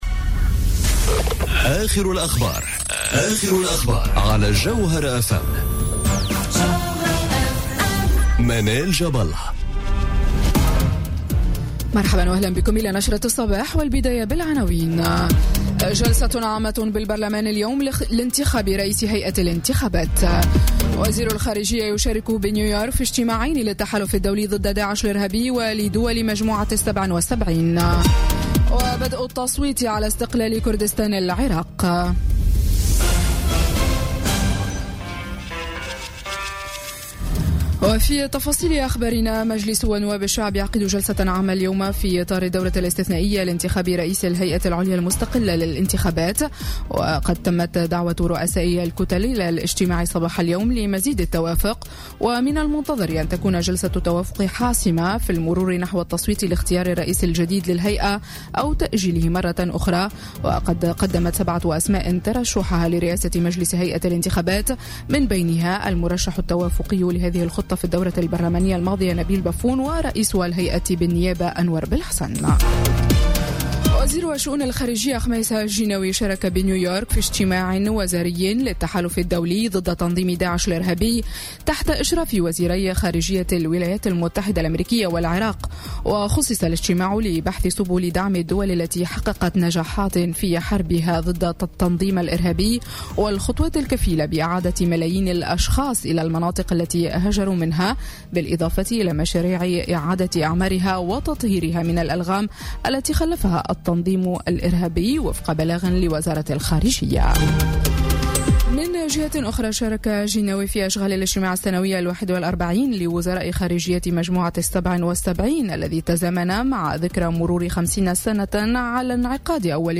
نشرة أخبار السابعة صباحا ليوم الإثنين 25 سبتمبر 2017